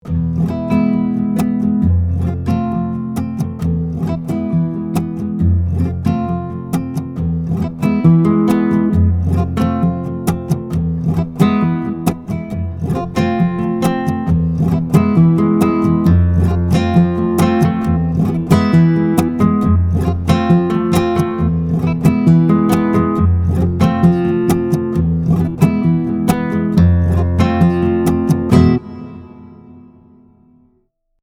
• Nylon Guitar
• Finger style, dry samples.